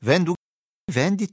Gravona et Rocca (sud)